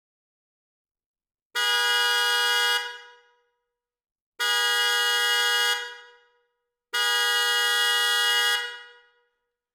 Deze luchthoorn werkt op 12V en combineert een hoge en een lage toon. Het model heeft één aansluitpunt en levert een geluidsniveau van 112dB.